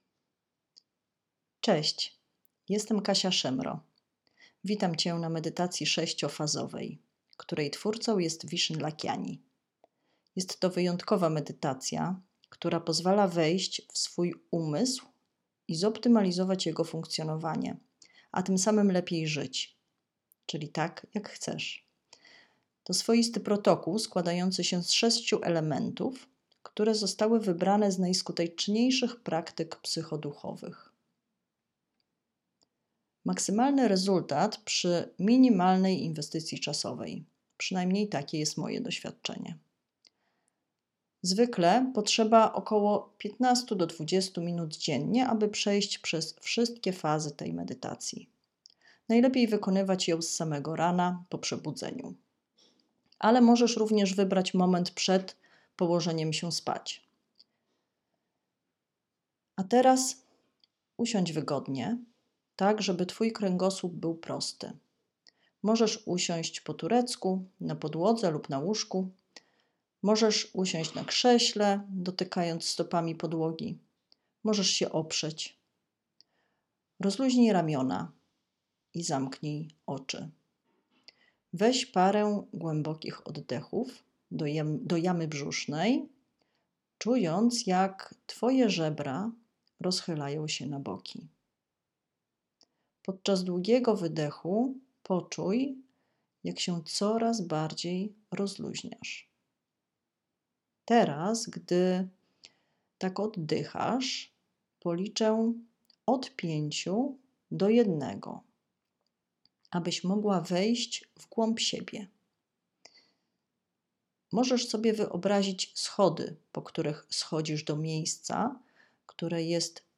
MEDYTACJA 6-FAZOWA